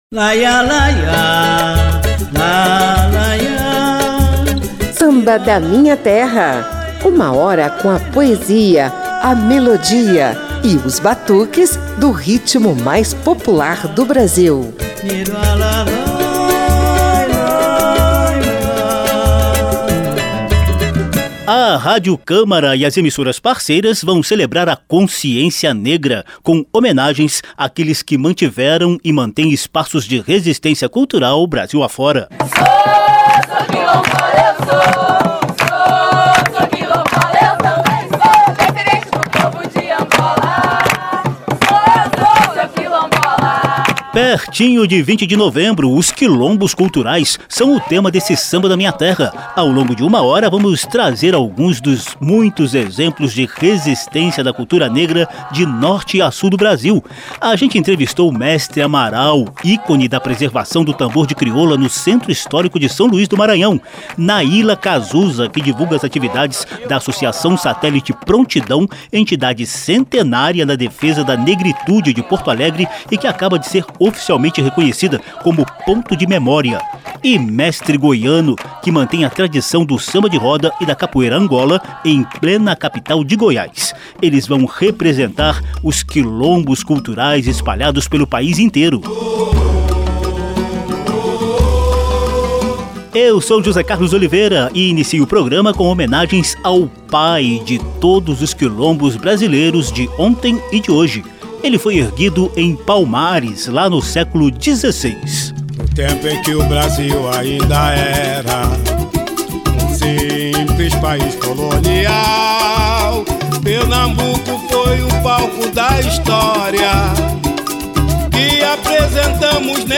Sambas de reverência aos quilombos de ontem e de hoje ilustram o programa.